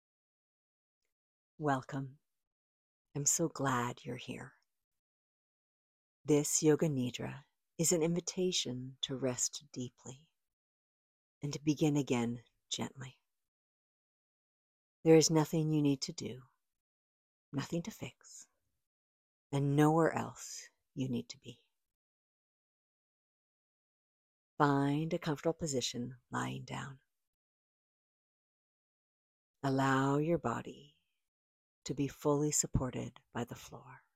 The Soft Beginnings Bundle pairs a guided reflection workbook with a calming Yoga Nidra audio to help you release the past, clarify your heart’s intention, and step gently into what’s next.
Relaxing Soundscapes
Each recording offers a unique blend of soothing sounds and guided meditations, crafted to support your journey towards inner peace and clarity.
Soft Beginnings: Yoga Nidra Sample
Soft-Beginnings-Nidra-sample.m4a